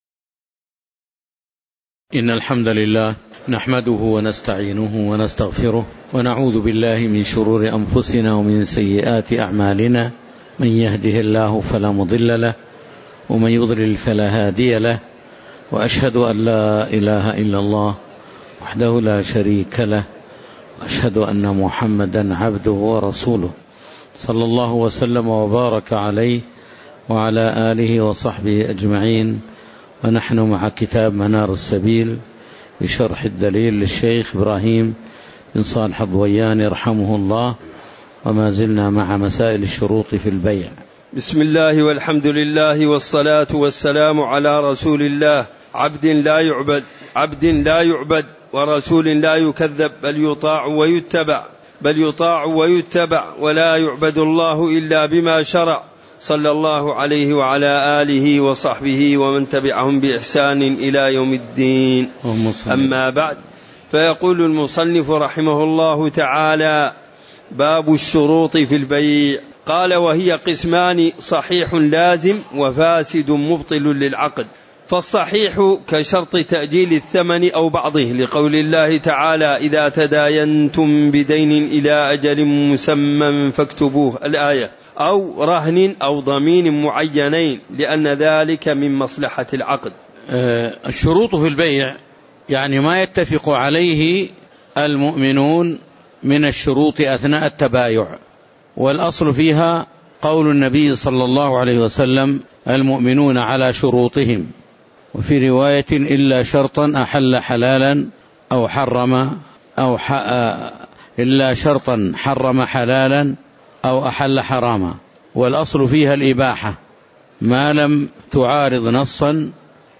تاريخ النشر ٦ محرم ١٤٤٠ هـ المكان: المسجد النبوي الشيخ